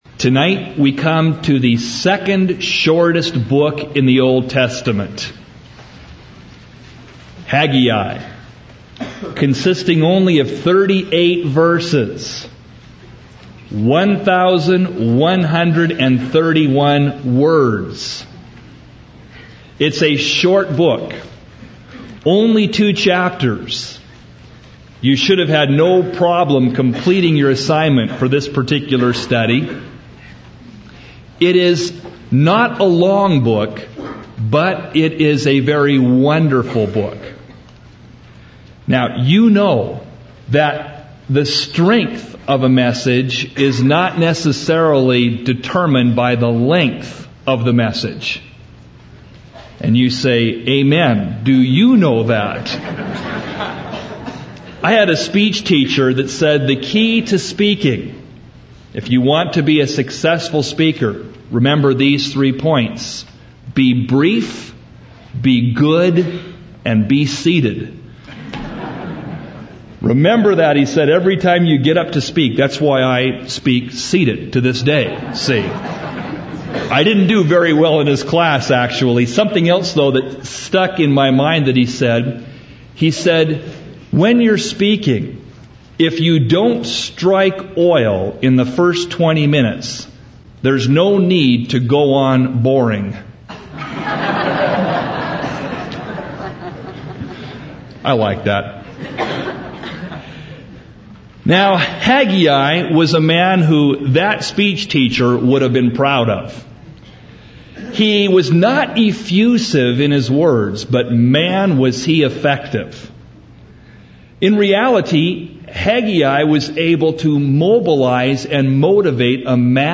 01 Verse by Verse Teaching